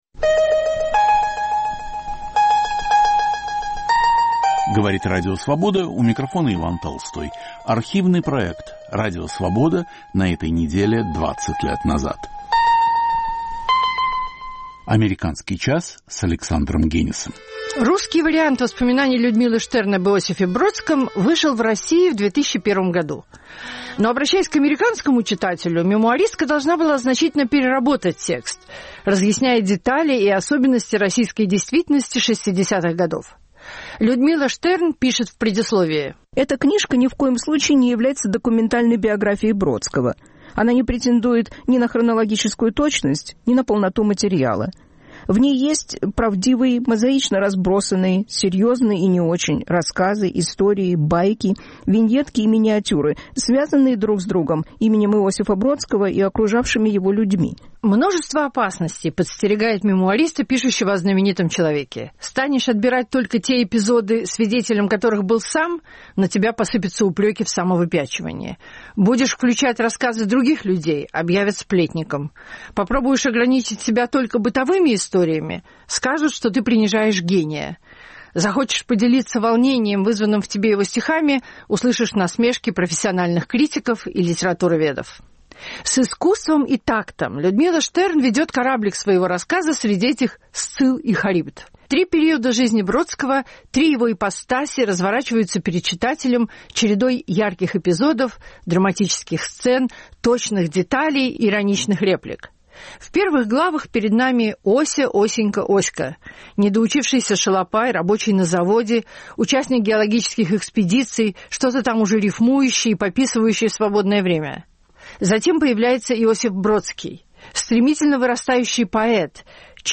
Ведет Александр Генис.